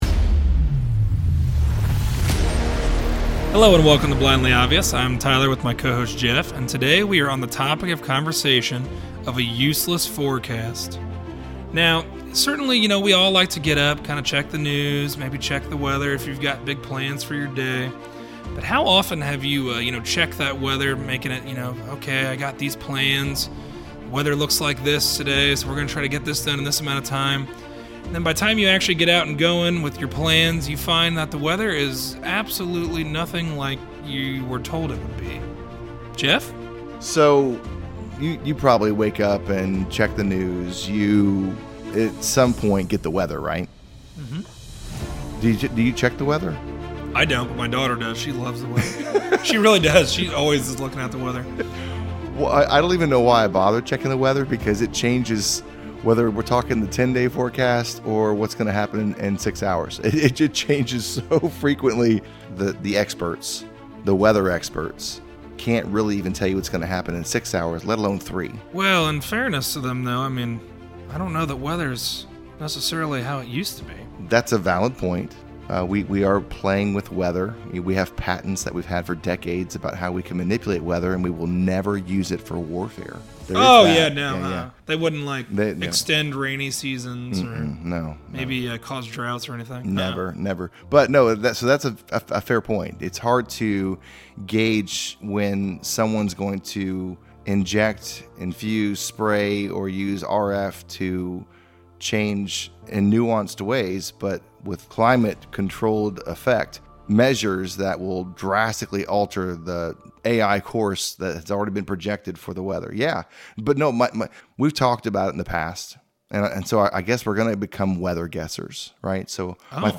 A conversation on the forecast and more often than not, it’s uselessness. Whether it be the weather, or forecasting our lives, how often do we prepare for what is certain only to find it wasn’t what we were led to believe?